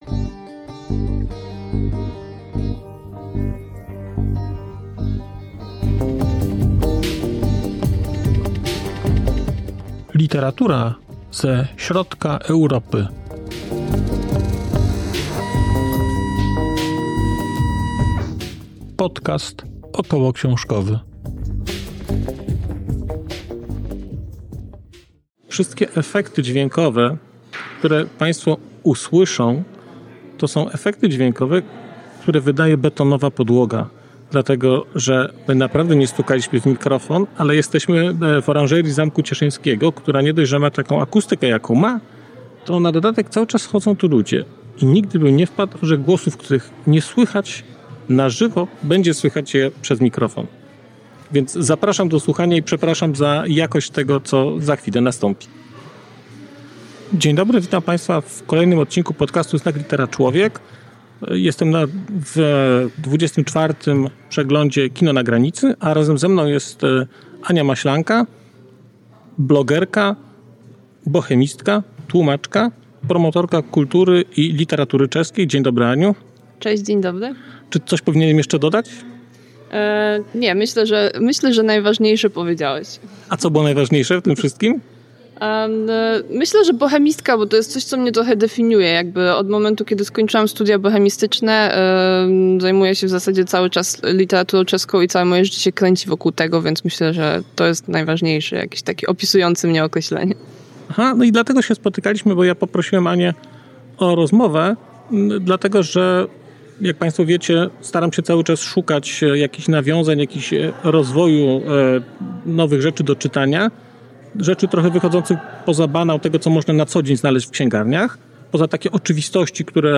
Rozmowa
nagrana w Cieszynie.